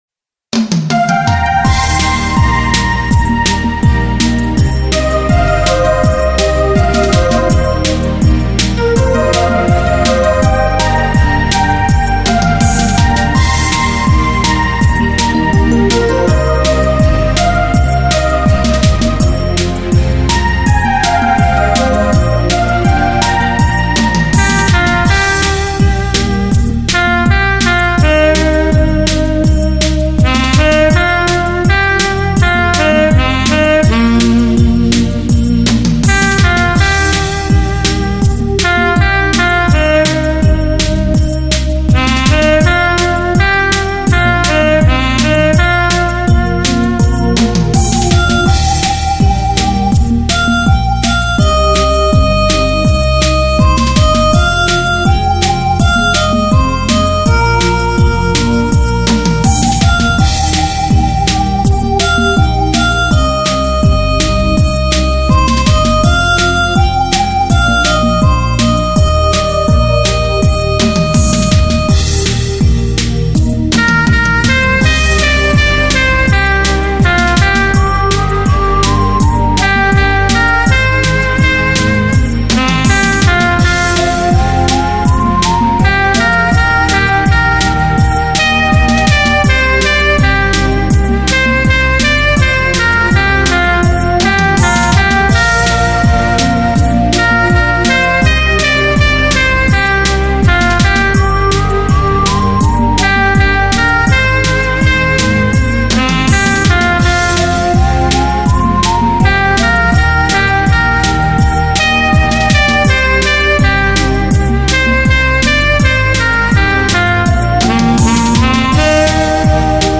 专辑格式：DTS-CD-5.1声道
（快四）